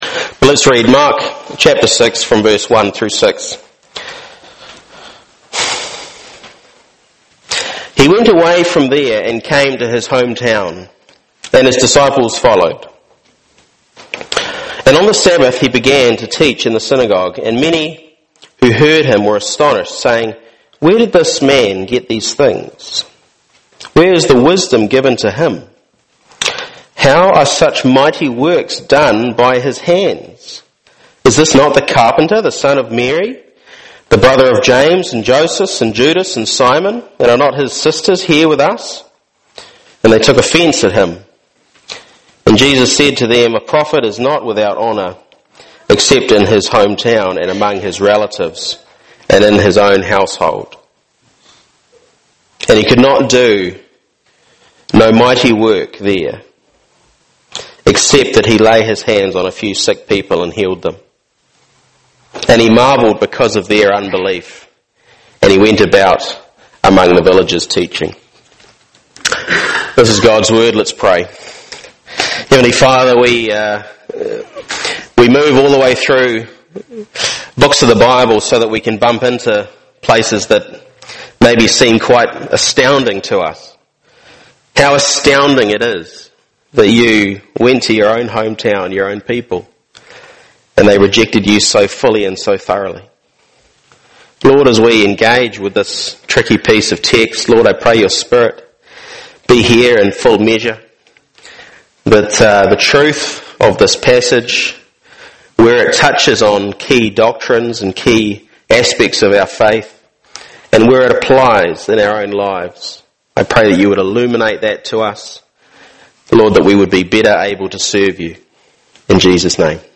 The Gospel of Mark Passage: Mark 6:1-6 Service Type: Family